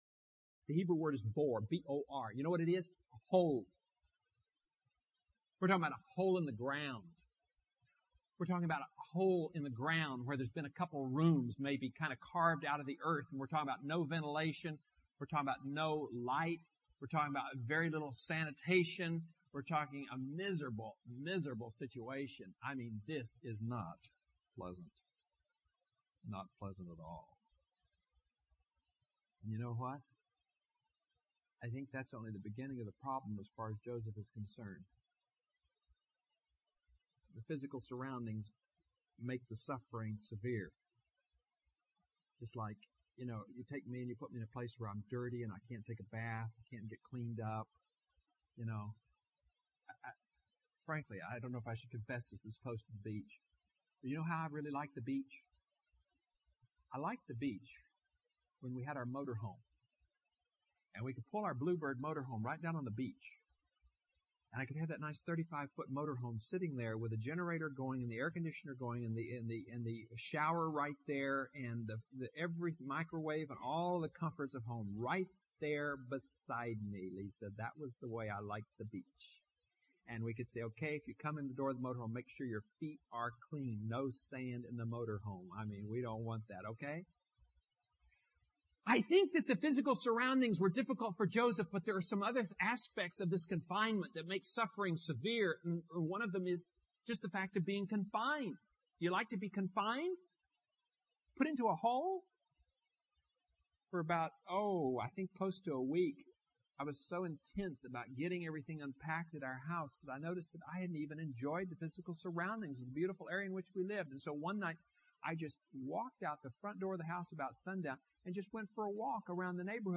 Online Sermon